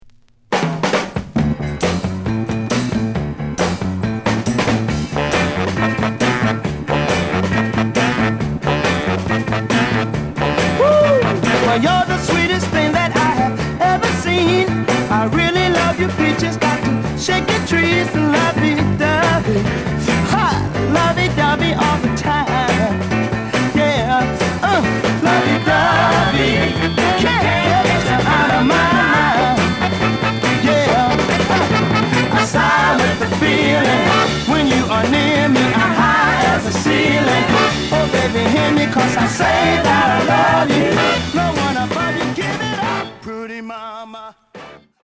The listen sample is recorded from the actual item.
●Genre: Rhythm And Blues / Rock 'n' Roll
Slight edge warp.